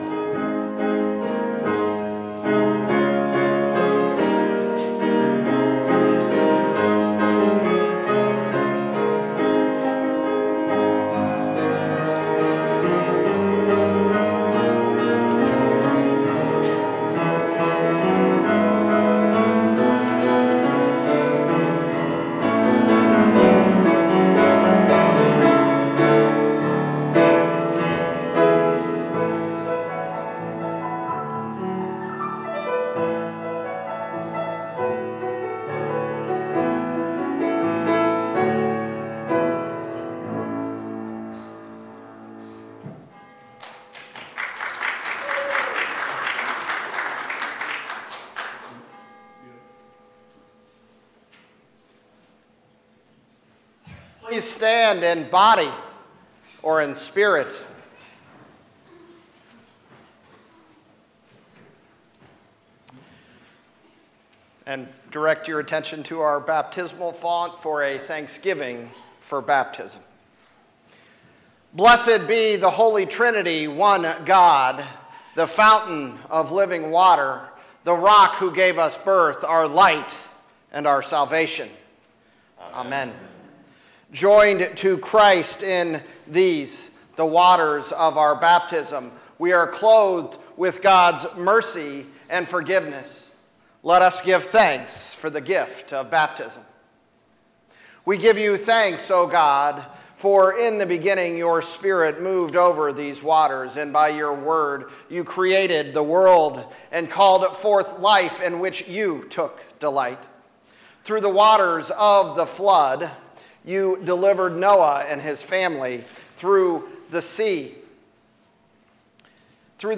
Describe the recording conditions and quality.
Wednesday, March 15th, Combined Lutheran Worship Service - First Lutheran Church